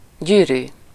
Ääntäminen
Synonyymit band schakel kring beugel rondweg Ääntäminen Tuntematon aksentti: IPA: /rɪŋ/ Haettu sana löytyi näillä lähdekielillä: hollanti Käännös Ääninäyte 1. körút 2. szorító 3. ring 4. gyűrű Suku: m .